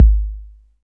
Index of /90_sSampleCDs/Roland L-CD701/KIK_Electronic/KIK_Cheesy K1
KIK 808 K 3.wav